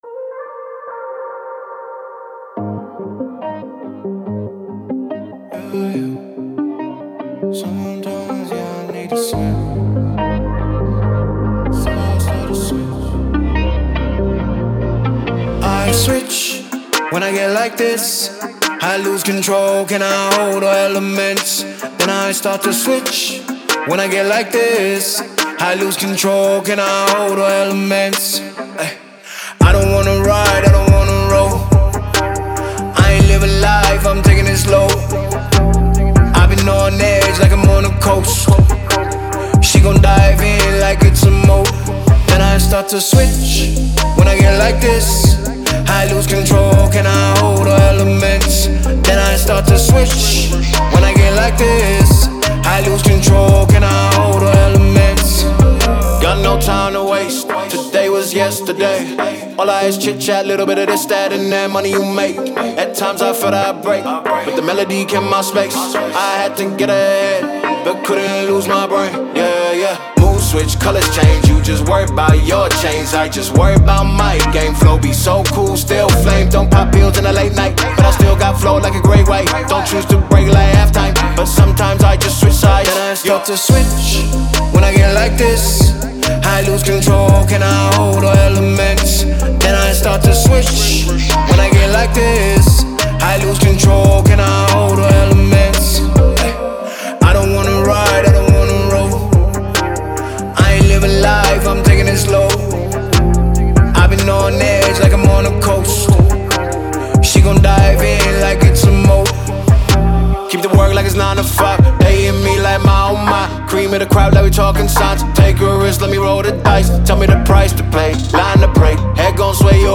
ژانرهای :  هیپ هاپ / رپ